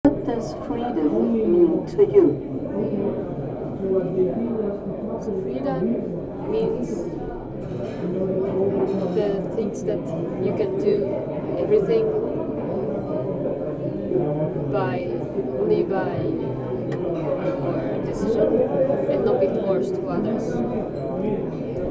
FALLING WALLS 2024 @ Falling Walls Science House, Berlin